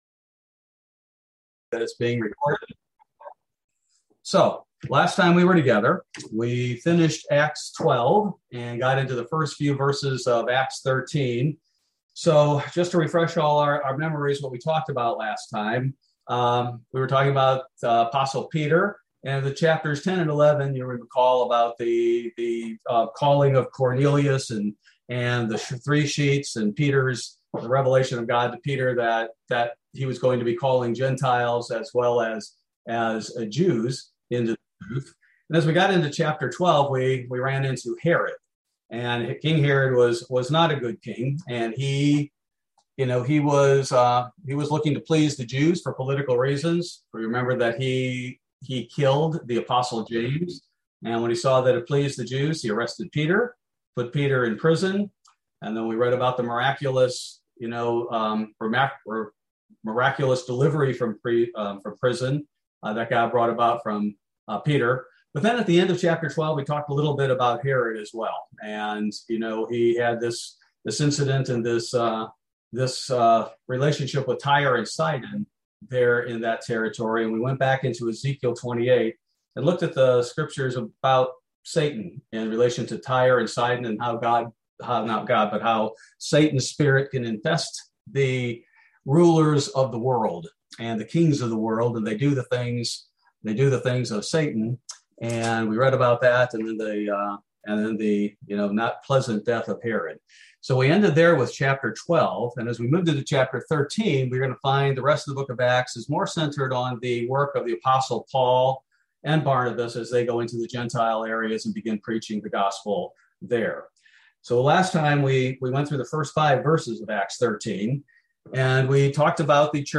Bible Study: August 25, 2021